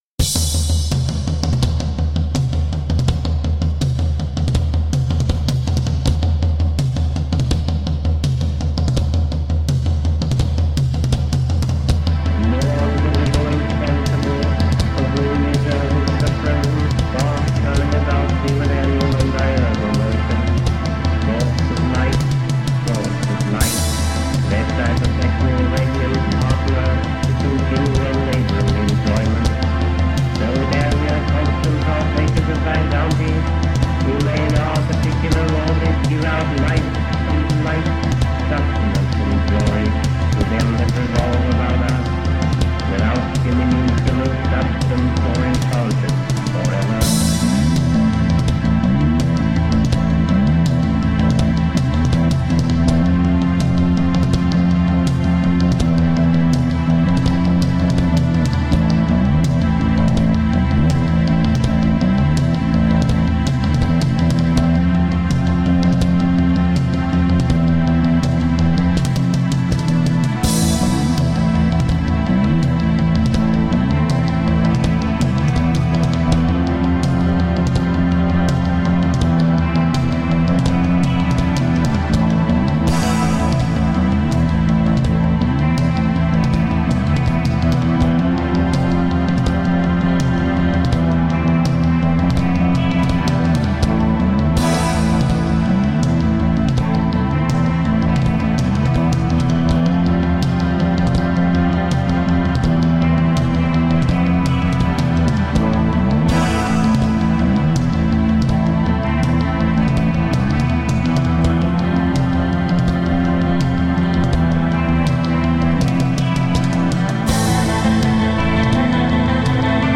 Intense goth rock from an ancient city.
Dark, dramatic feelings guaranteed.